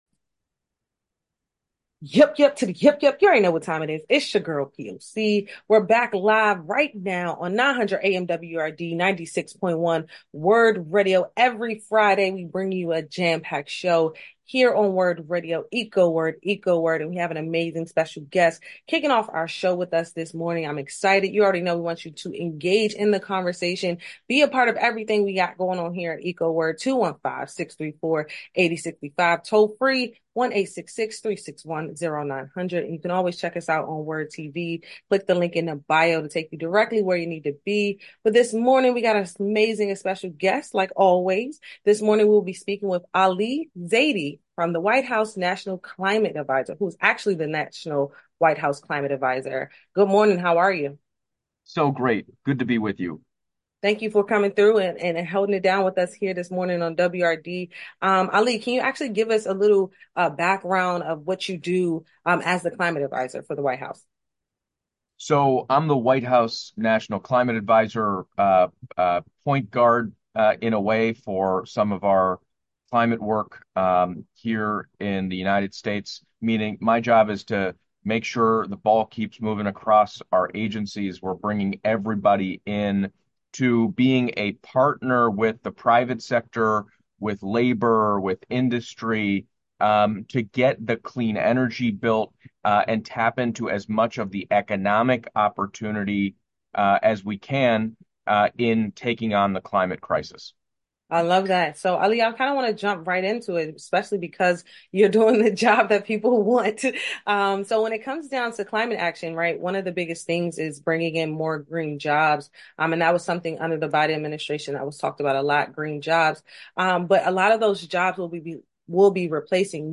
We spoke with Ali Zaidi, White House National Climate Advisor, who discussed the Biden-Harris Administration’s climate agenda during Climate Week. Zaidi emphasized the administration’s approach to tackling the climate crisis, which focuses on creating good-paying union jobs while lowering energy bills for families nationwide.